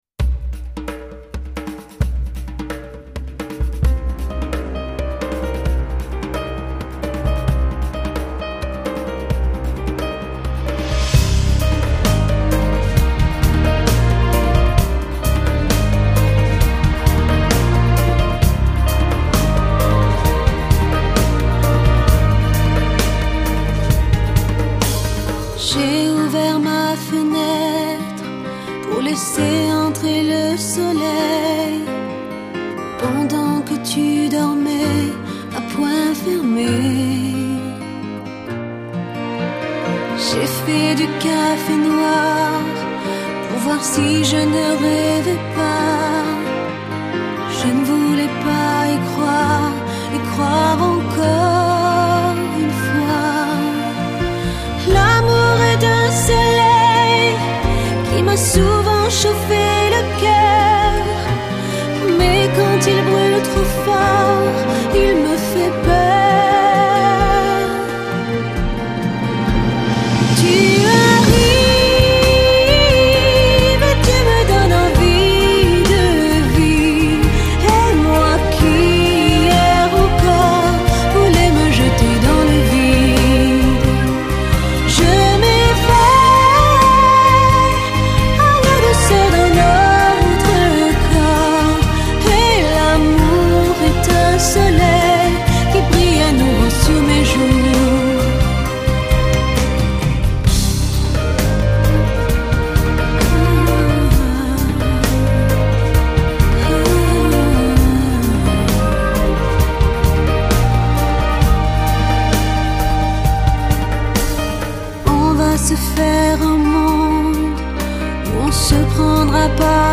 有人叫她法国温婉天后，有人叫她疗伤天后，她的歌声，只要飘扬在空气中便能舒缓人的心灵。